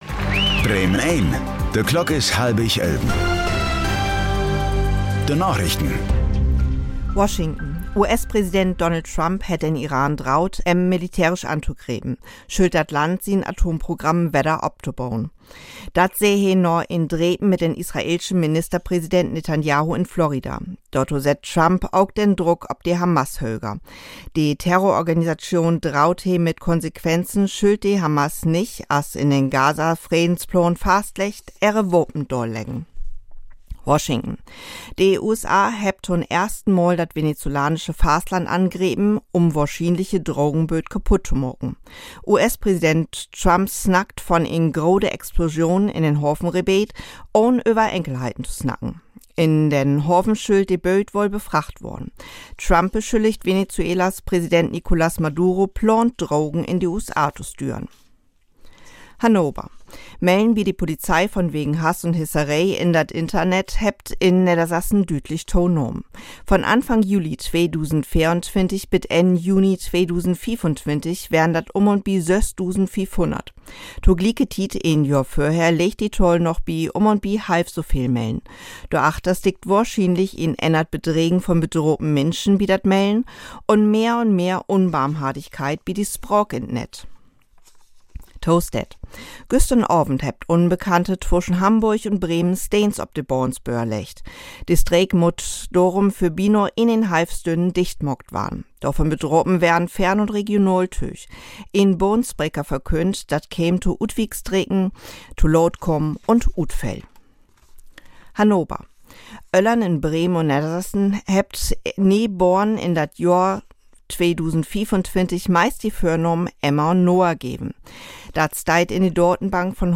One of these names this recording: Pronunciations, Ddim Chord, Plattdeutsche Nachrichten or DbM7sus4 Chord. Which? Plattdeutsche Nachrichten